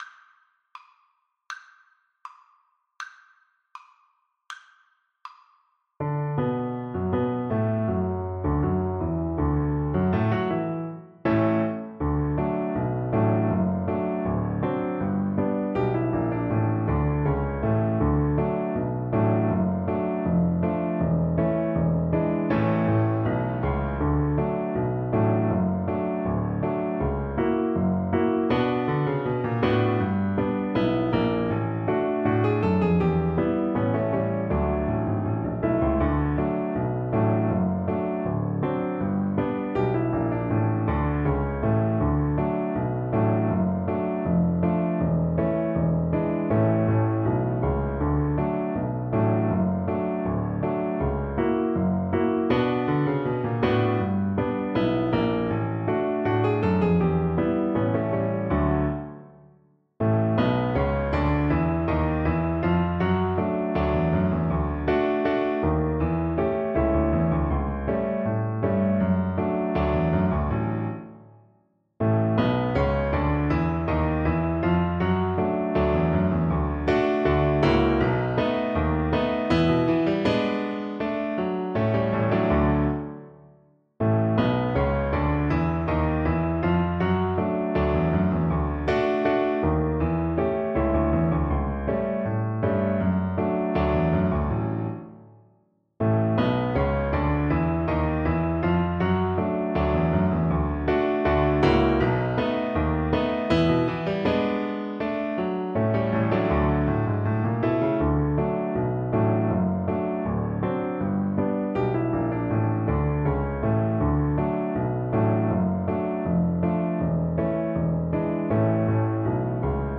Violin
2/4 (View more 2/4 Music)
Slow March Tempo = 80
Jazz (View more Jazz Violin Music)